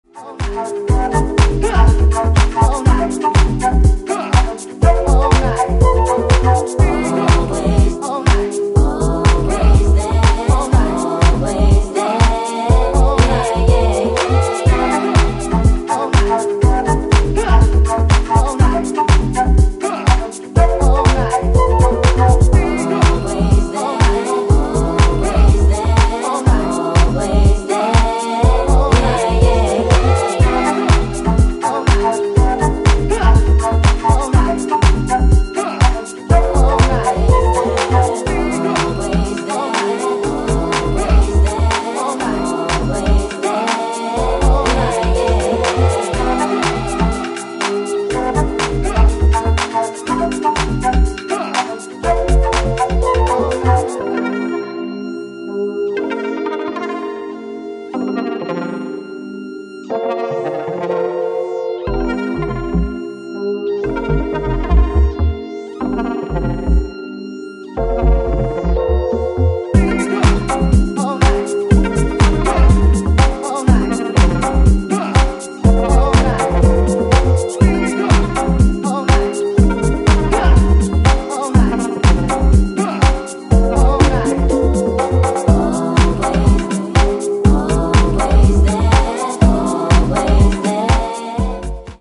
2. > HOUSE・TECHNO
ジャンル(スタイル) HOUSE / DEEP HOUSE